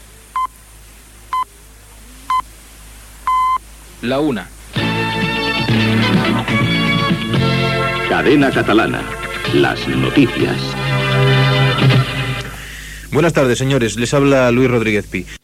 Senyals horaris, hora, careta del butlletí i nom del redactor.
Informatiu